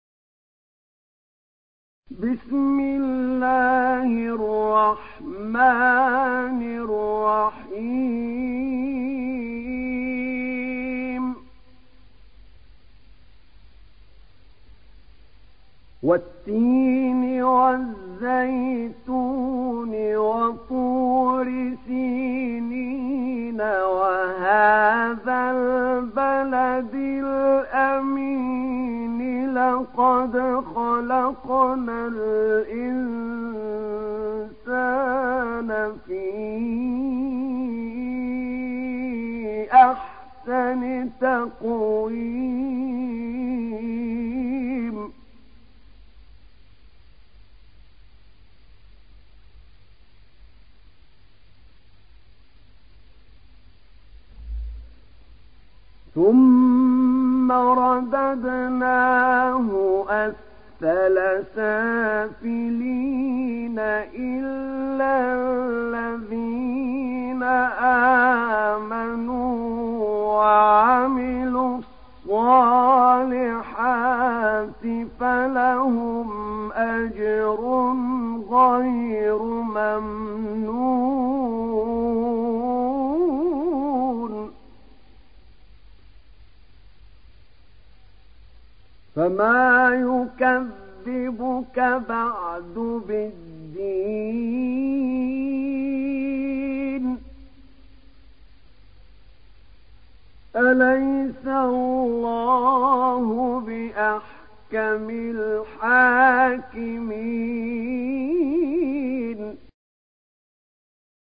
تحميل سورة التين mp3 بصوت أحمد نعينع برواية حفص عن عاصم, تحميل استماع القرآن الكريم على الجوال mp3 كاملا بروابط مباشرة وسريعة